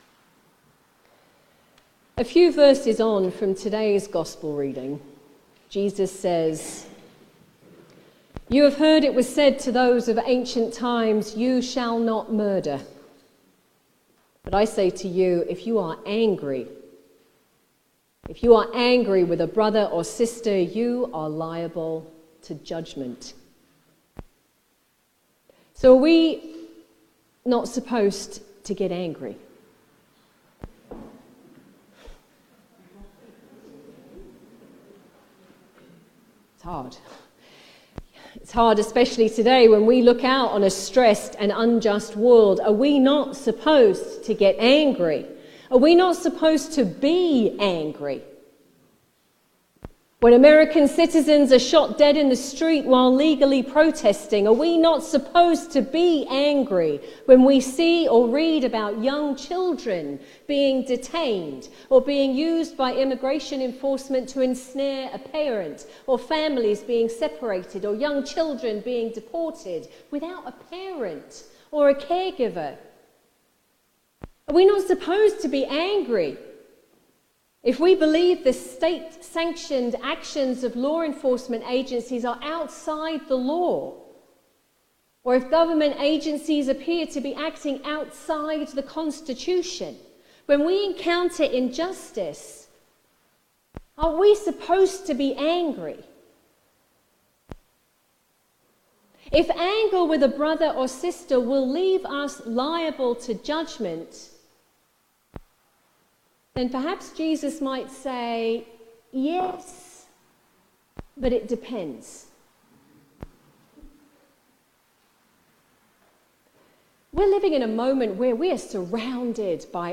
Feb-1st-Sermon.mp3